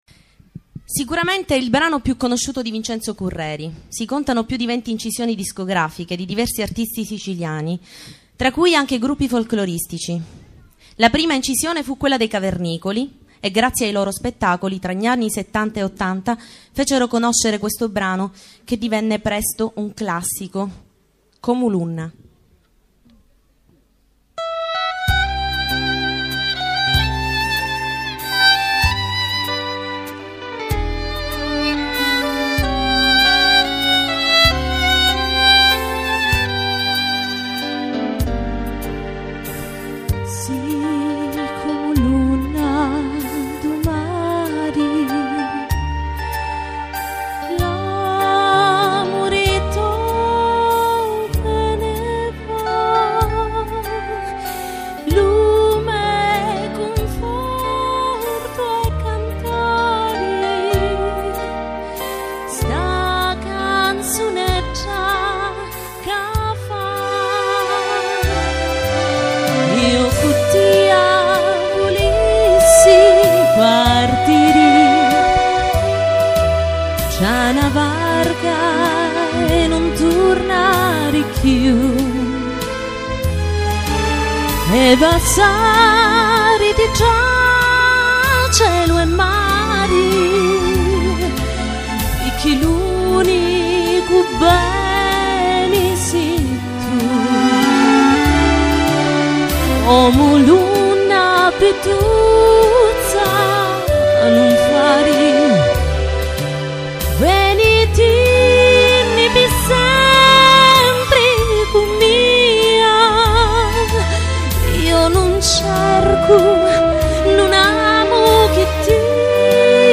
clarinetto e sax alto
voce e violino